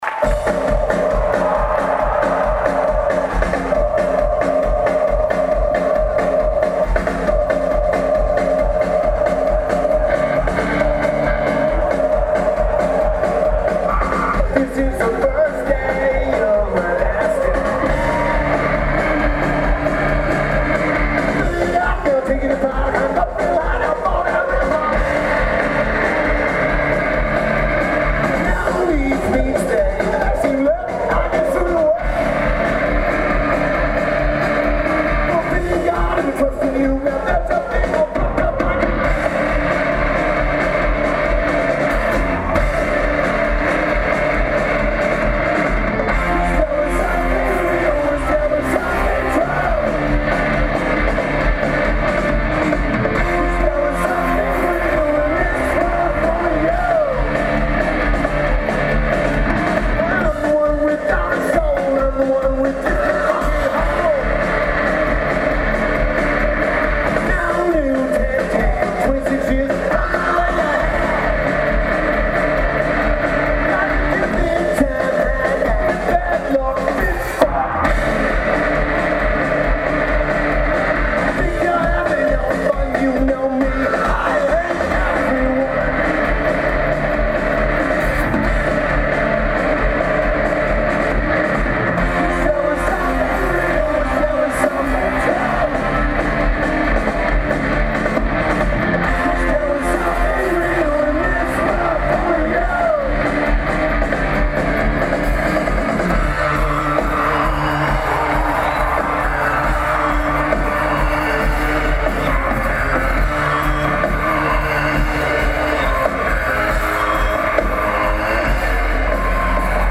Lineage: Audio - AUD (Olympus Voice-Trek V-75)